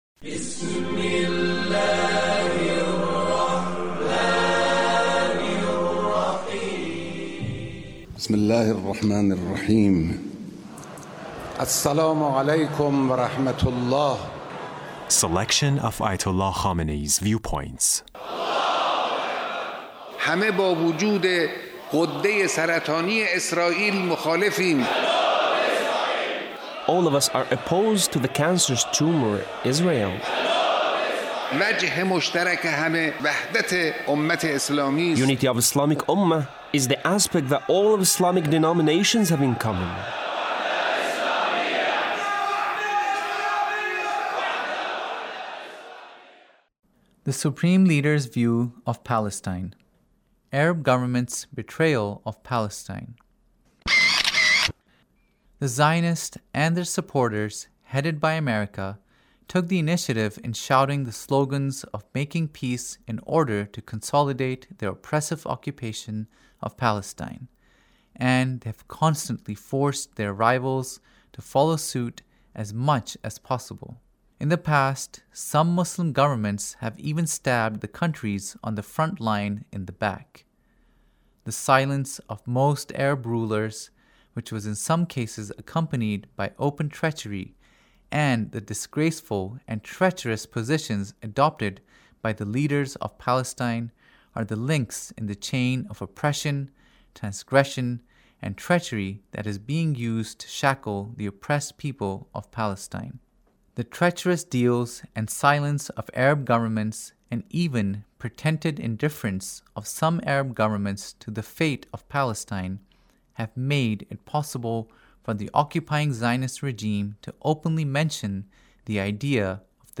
Leader's Speech (1879)
Leader's Speech on Palestine